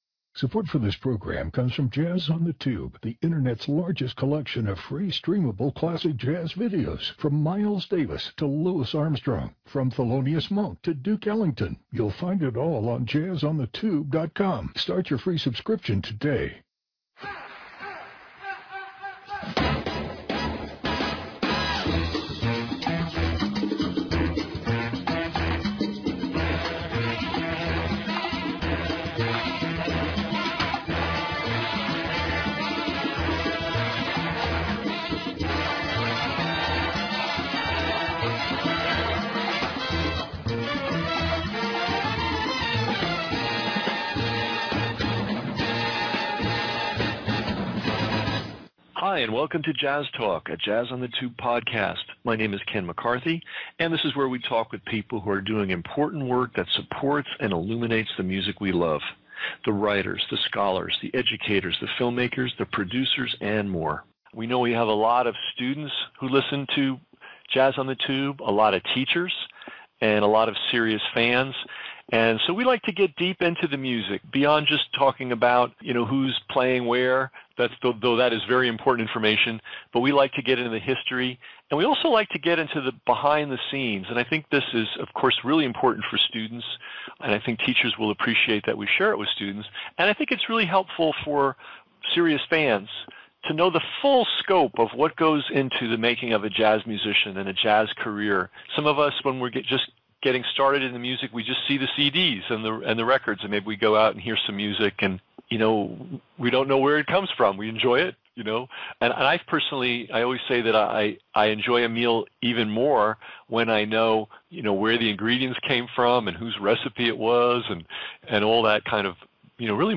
Artist-Educators, Chroniclers, Jazz on the Tube Interview, Podcasts